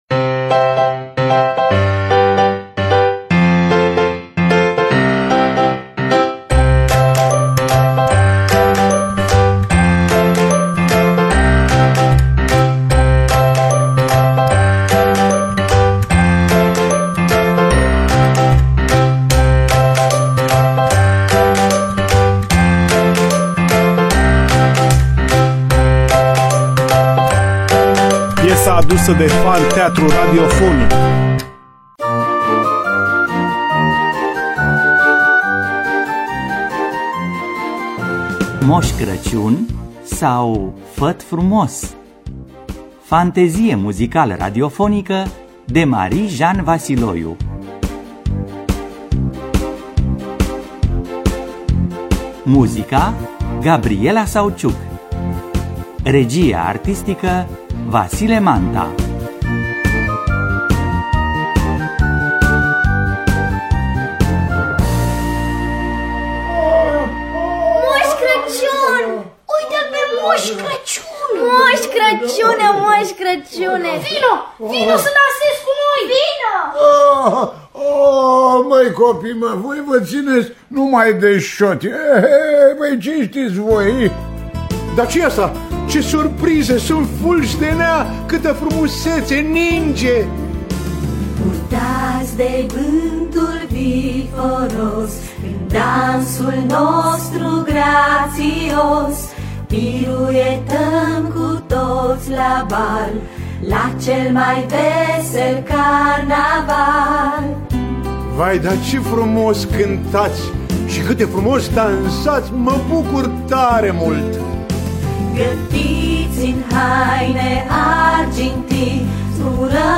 Maria Ioana Vasiloiu – Mos Craciun Sau… Fat Frumos (2008) – Teatru Radiofonic Online